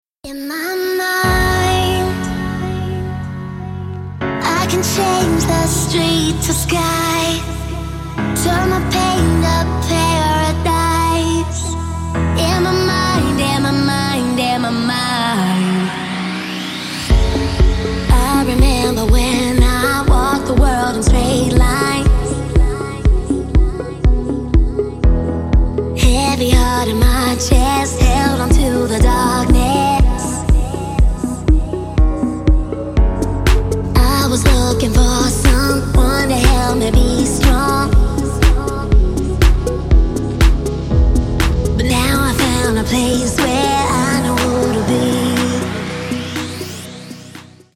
• Качество: 160, Stereo
красивые
женский вокал
dance
Electronic
EDM
спокойные
club
house
vocal